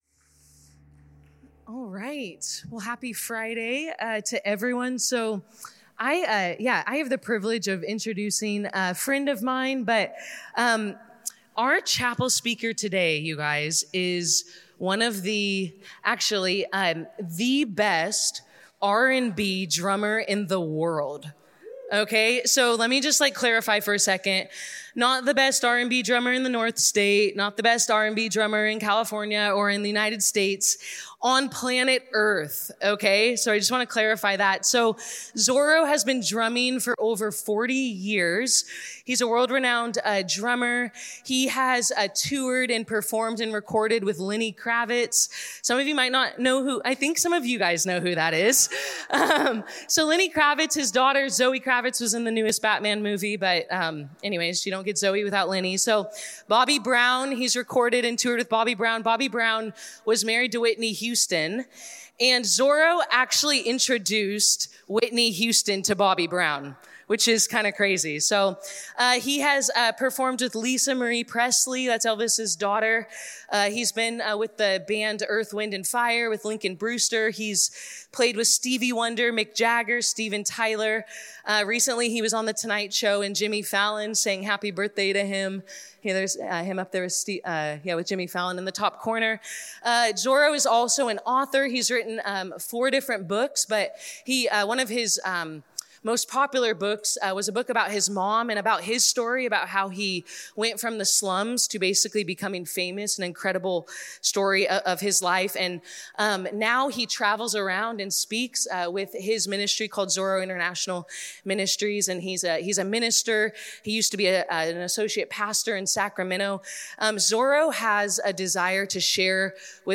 This talk was given in chapel on Friday, February 21st, 2025 God Bless you.